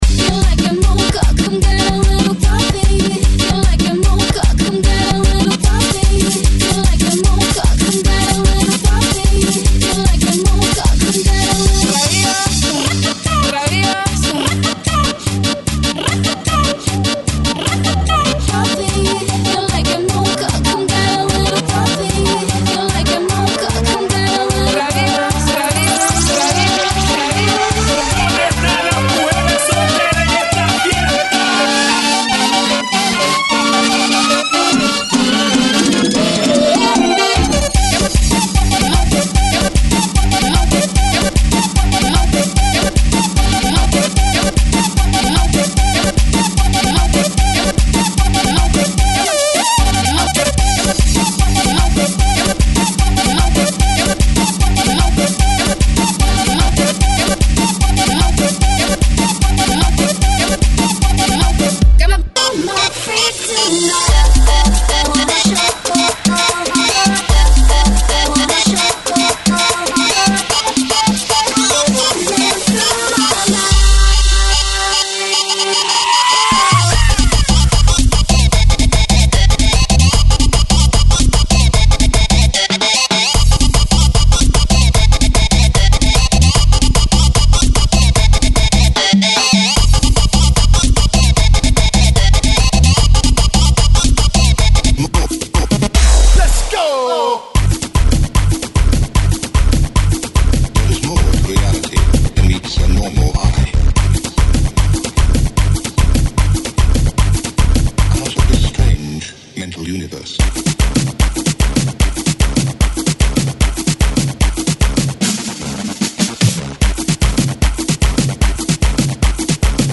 GENERO: LATINO – TRIBAL